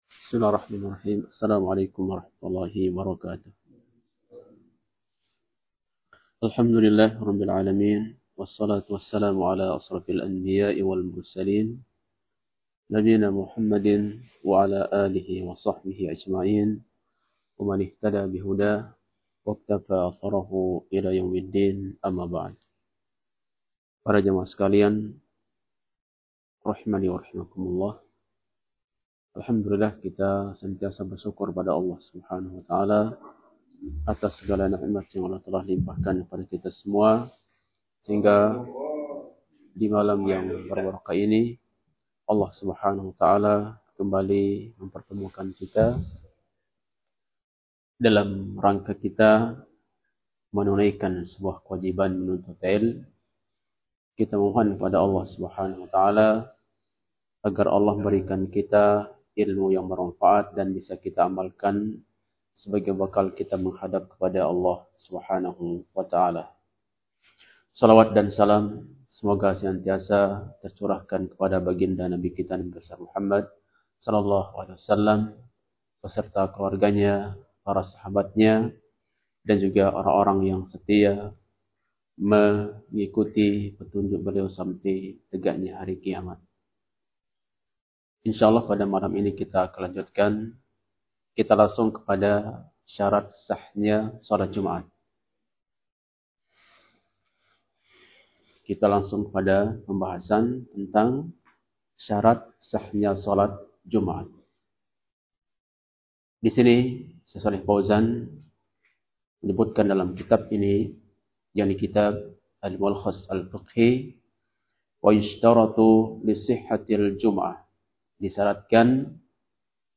Kajian Ahad – Doha Membahas